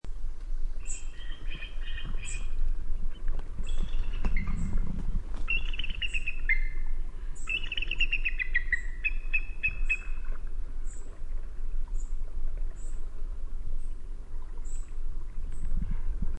Download Bald Eagle sound effect for free.
Bald Eagle